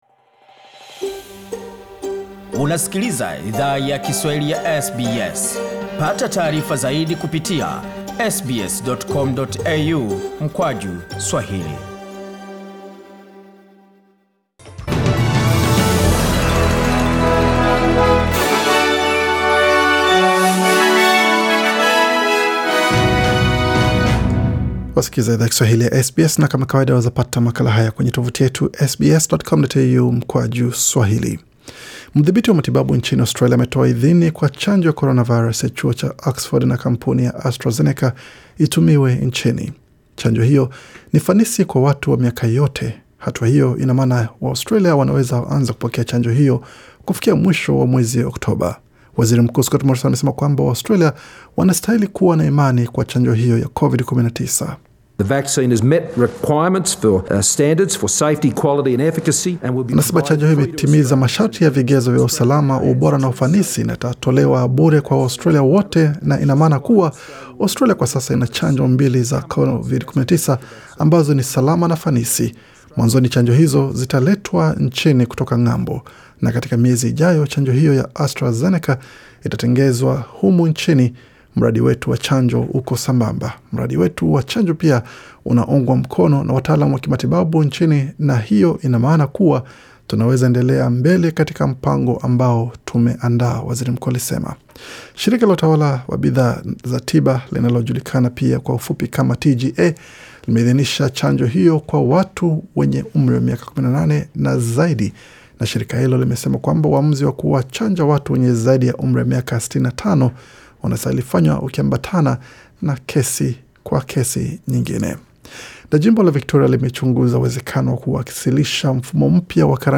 Taarifa ya habari 16 Februari 2021